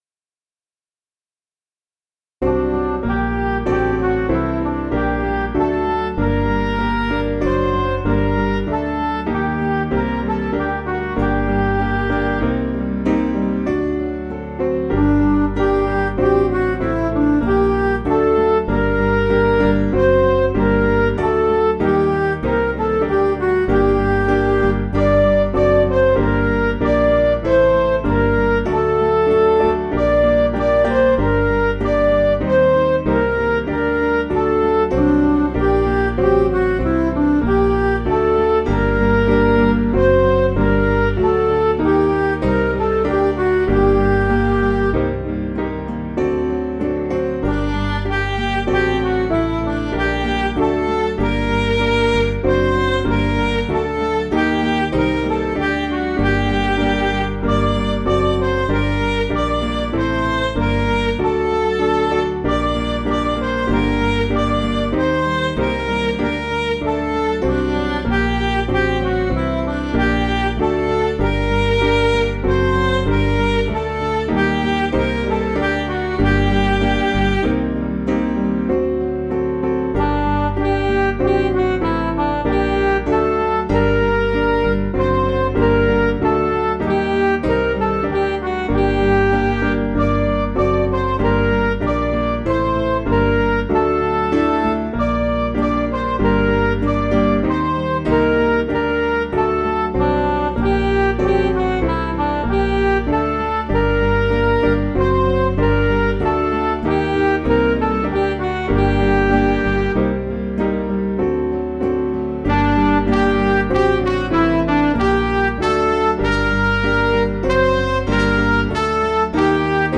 Piano & Instrumental
(CM)   4/Gm